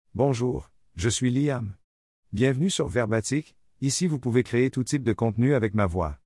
Liam — Male Canadian French AI voice
Liam is a male AI voice for Canadian French.
Voice sample
Male
Liam delivers clear pronunciation with authentic Canadian French intonation, making your content sound professionally produced.